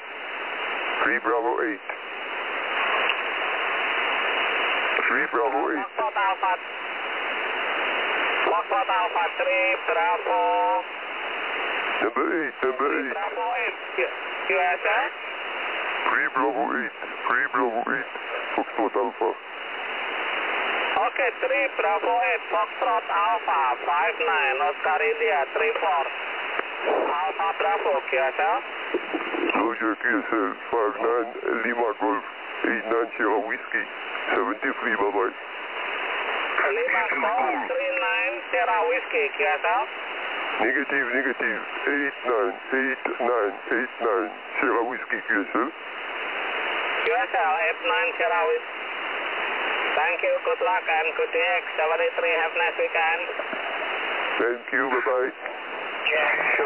QSO